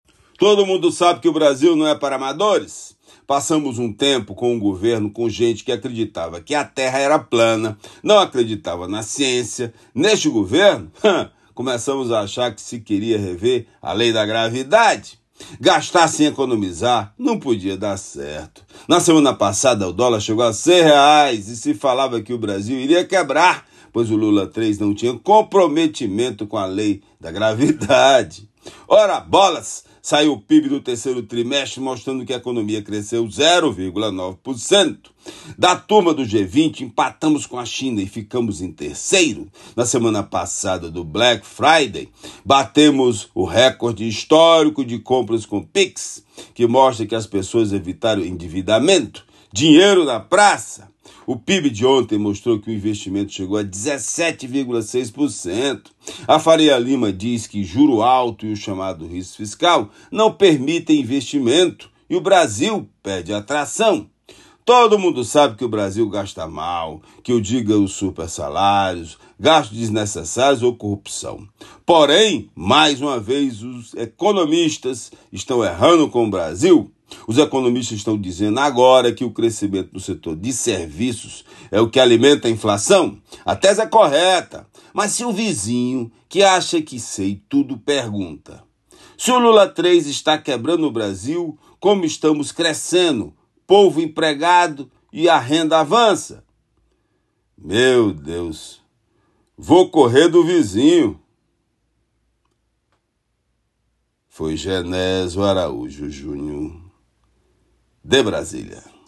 Comentário
direto de Brasília.